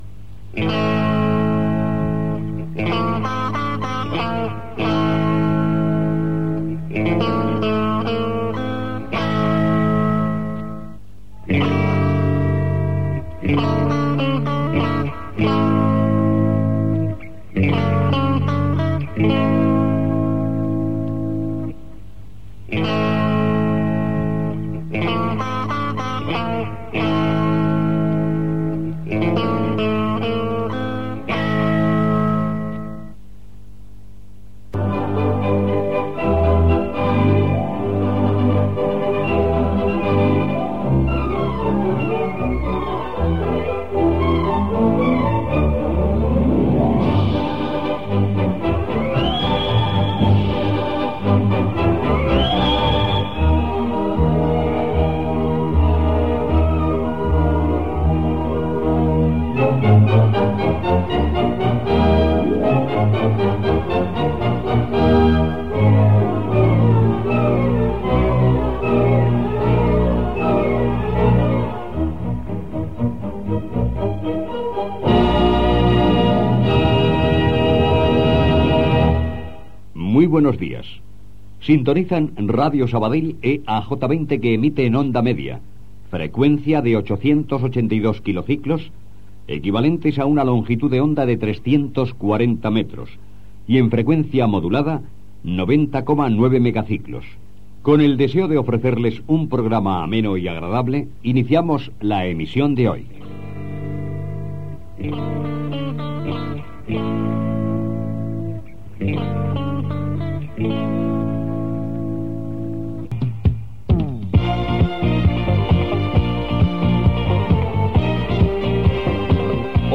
Inici d'emissió. Butlletí informatiu: estatut autonomia de Múrcia, campanya anti OTAN, etc.
Informatiu
FM